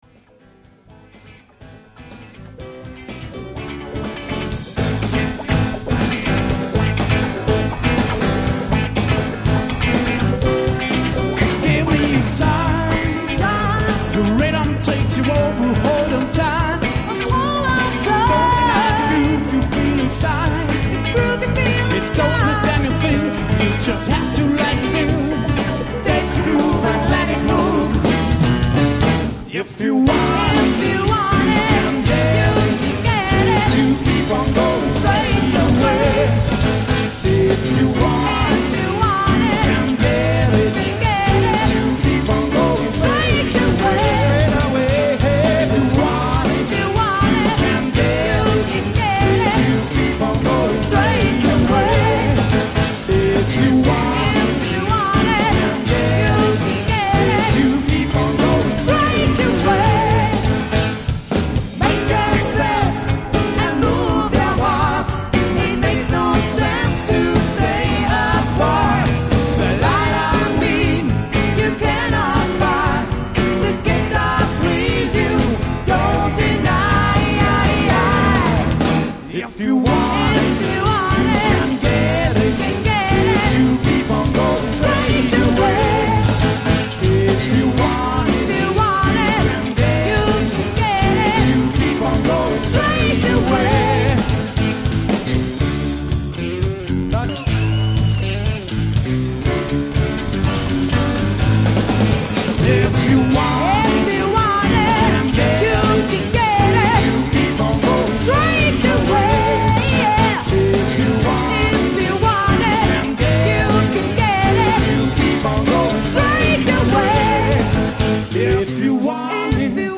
Live-Mitschnitt Altes Kino Mels 1999
Lead Vocals & Bass